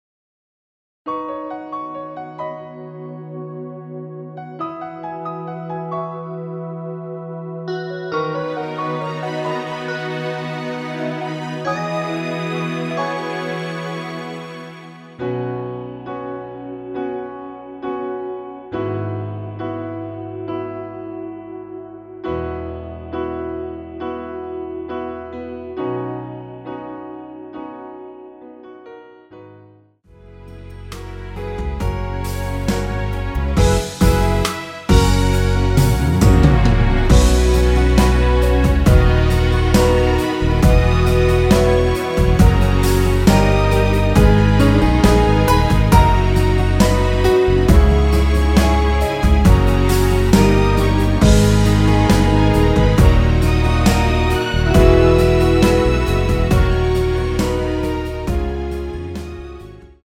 남자키에서 (+2) 올린 MR 입니다.
앞부분30초, 뒷부분30초씩 편집해서 올려 드리고 있습니다.